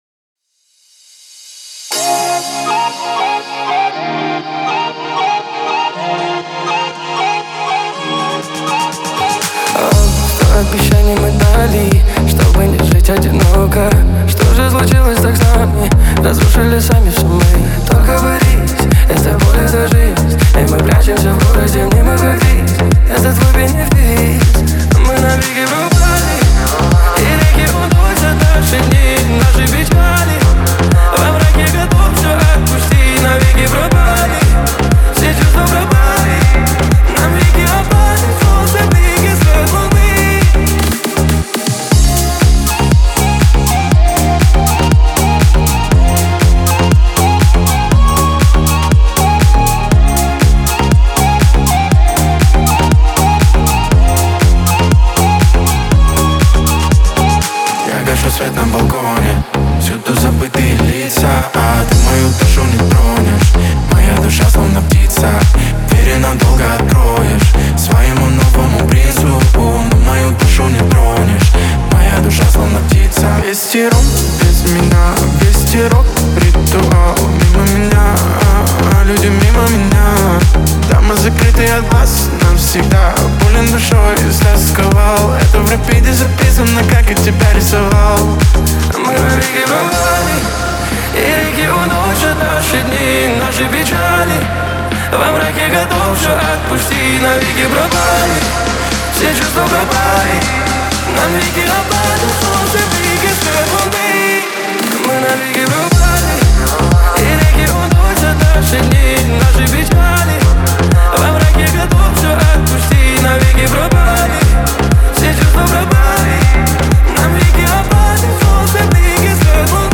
это яркий трек в жанре поп с элементами электронной музыки.